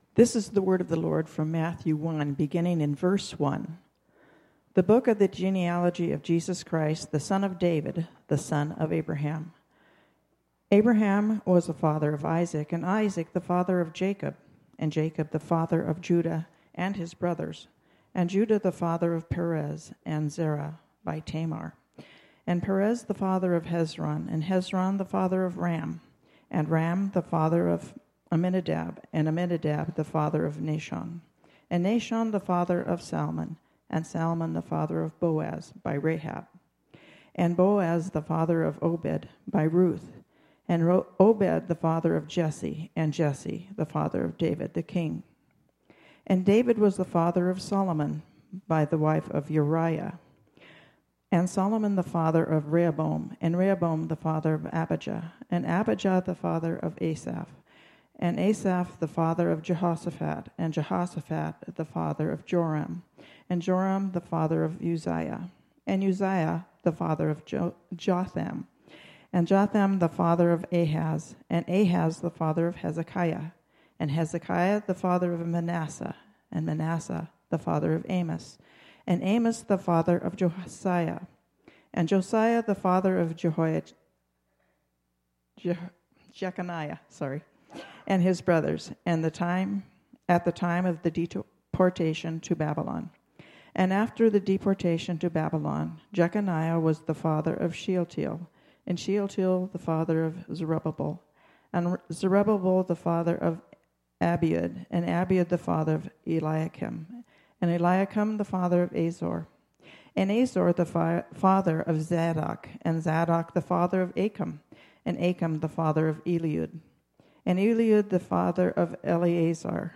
Matthew 1 Service Type: Sunday Morning Bible Text